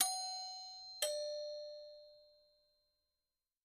Door Bells; Household Door Bells 3